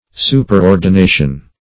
Superordination \Su`per*or`di*na"tion\, n. [Pref. super- +